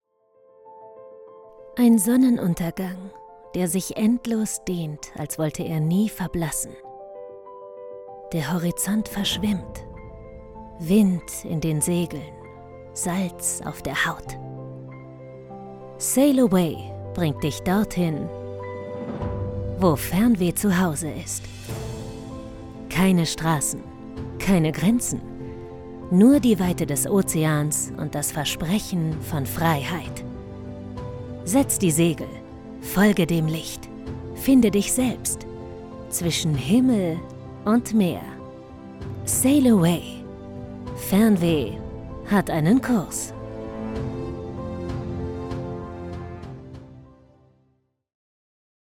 sehr variabel
Jung (18-30)
Commercial (Werbung), Presentation